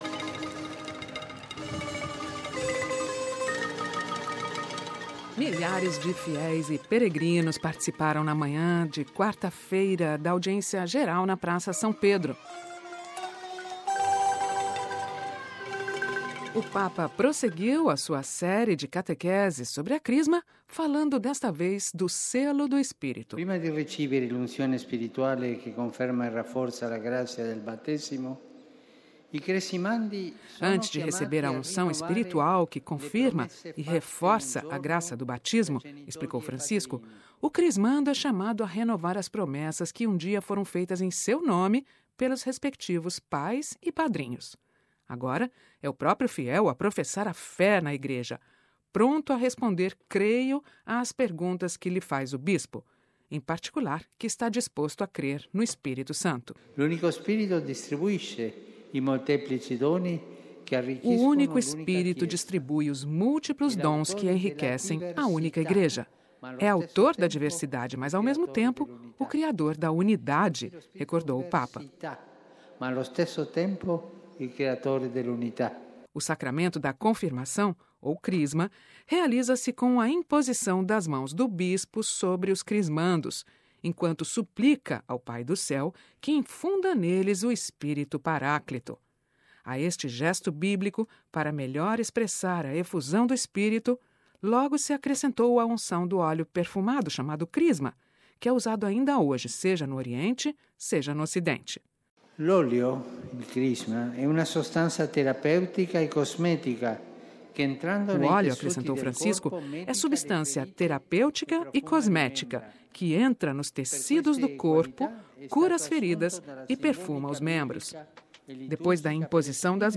Milhares de fiéis e peregrinos participaram na manhã de quarta-feira (30/05) da Audiência Geral na Praça S. Pedro.
Ouça a reportagem com a voz do Papa Francisco